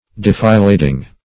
Defilading - definition of Defilading - synonyms, pronunciation, spelling from Free Dictionary
Defilading \De`fi*lad"ing\, n. (Mil.)